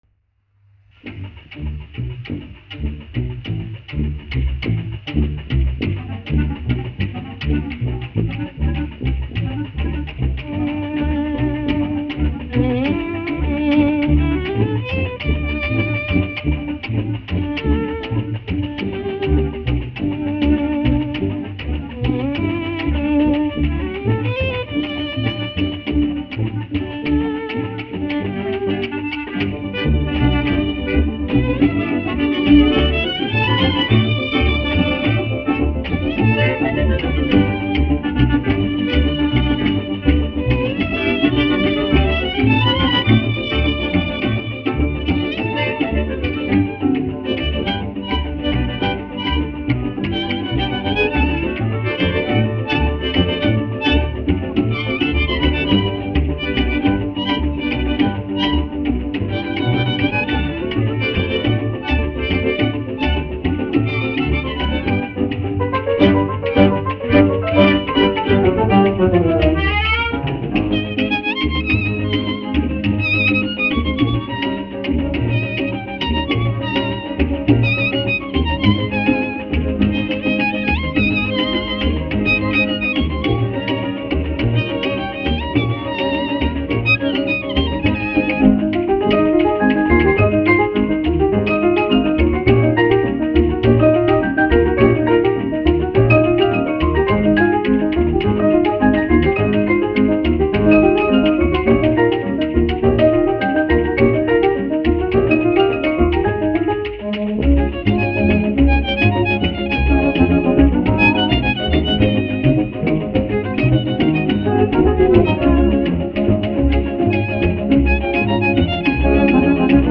1 skpl. : analogs, 78 apgr/min, mono ; 25 cm
Tautas mūzika--Meksika
Skaņuplate
Latvijas vēsturiskie šellaka skaņuplašu ieraksti (Kolekcija)